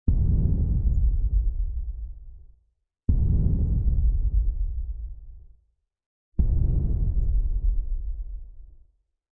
Scary Foley - Botón de Efecto Sonoro